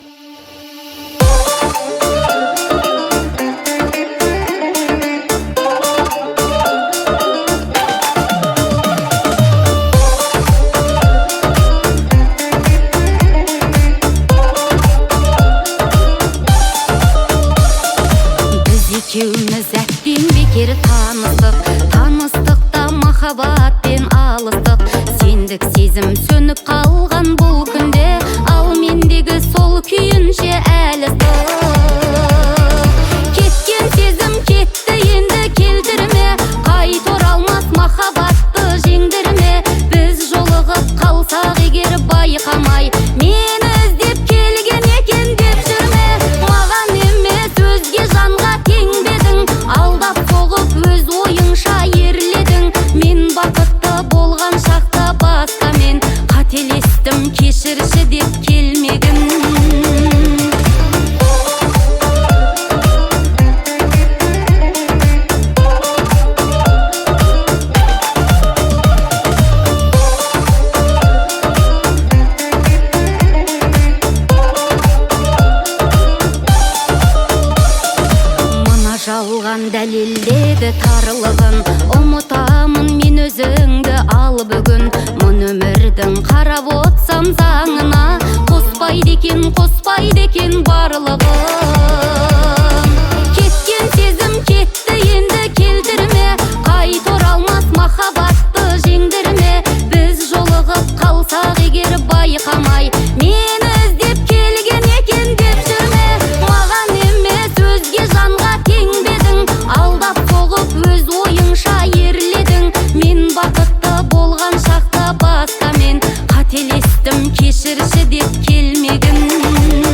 Казахская музыка